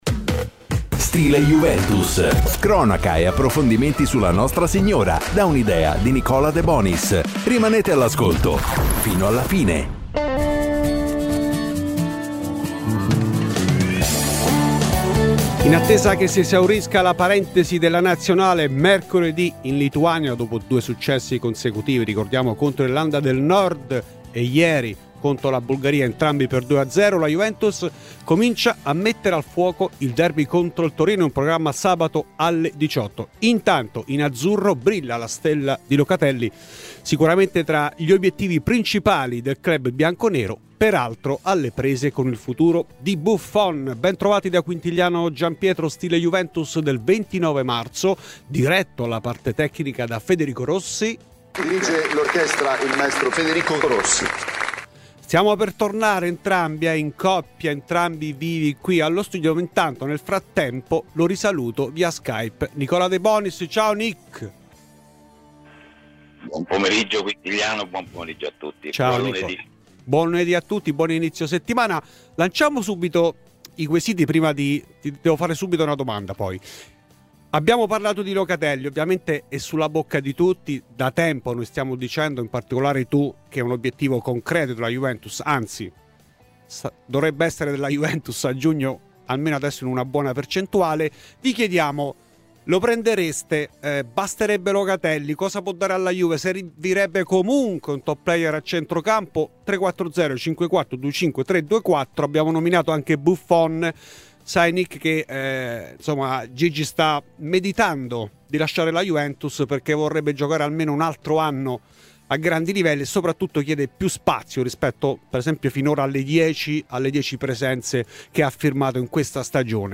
Clicca sul podcast in calce per ascoltare la trasmissione integrale.